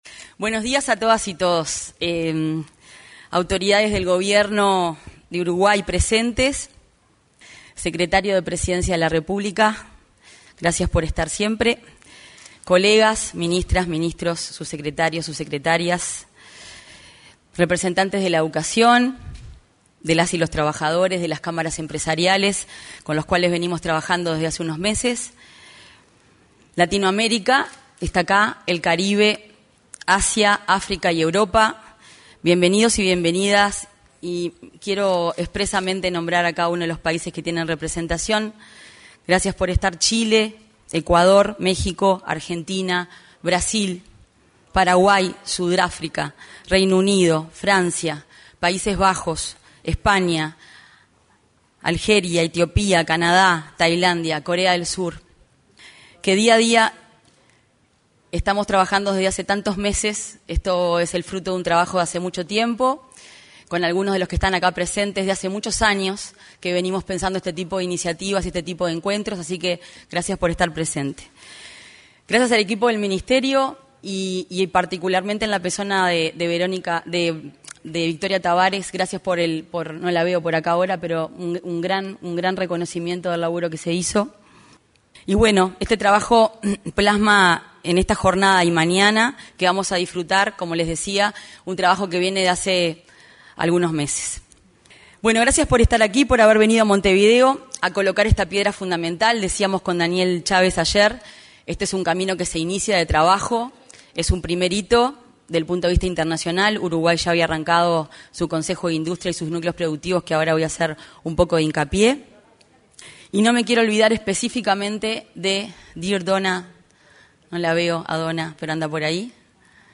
Se realizó, en el auditorio de la Torre Ejecutiva, la reunión Política Industrial y Finanzas - Iniciativa Uruguay Sur.